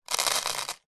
На этой странице собраны натуральные звуки семечек: от раскалывания скорлупы зубами до шуршания шелухи.
Насыпаем семечки в пластиковый контейнер с хрустом